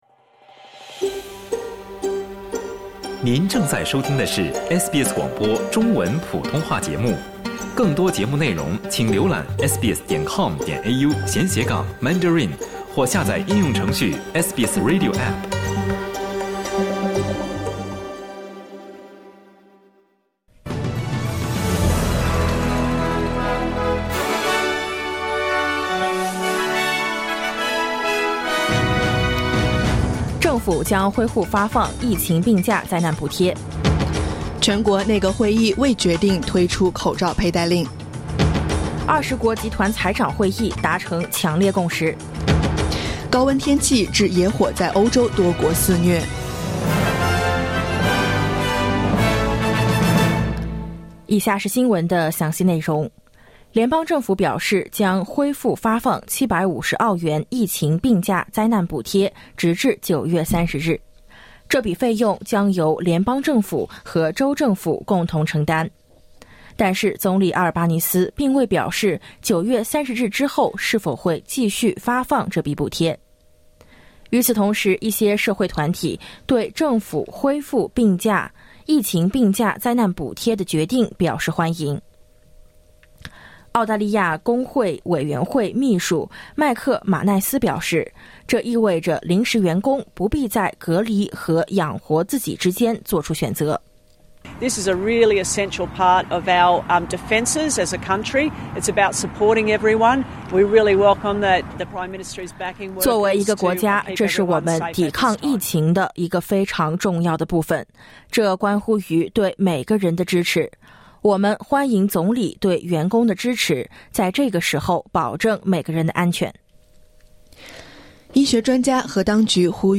SBS早新聞（2022年7月17日）